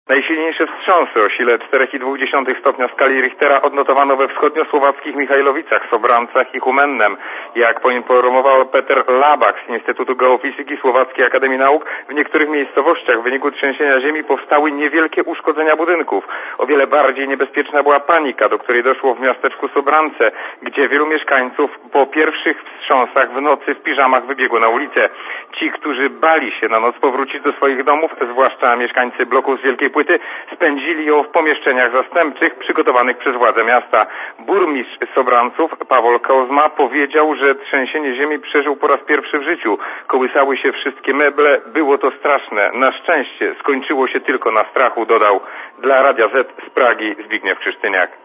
Źródło zdjęć: © Archiwum 21.05.2003 | aktual.: 21.05.2003 14:57 ZAPISZ UDOSTĘPNIJ SKOMENTUJ © (RadioZet) Korespondecja z Czech (190Kb)